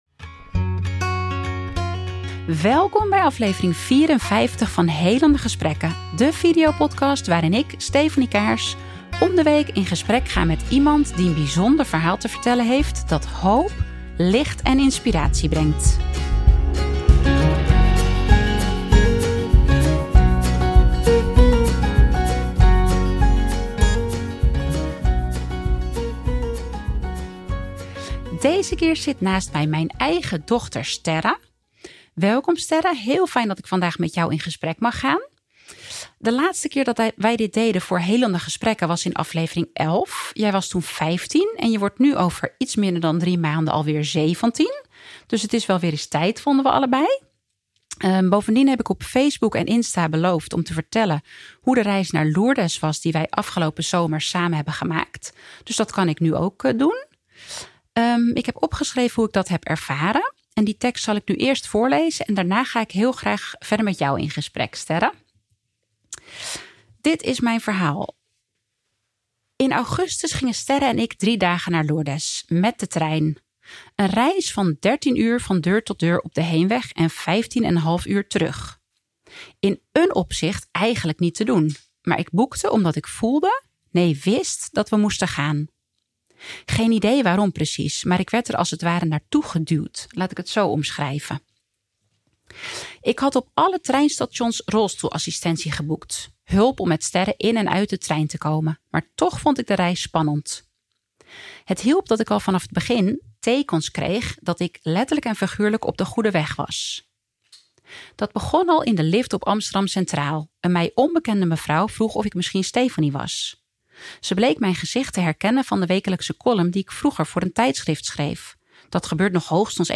waarin ik om de week in gesprek ga met iemand die een bijzonder verhaal te vertellen heeft dat hoop, licht en inspiratie brengt.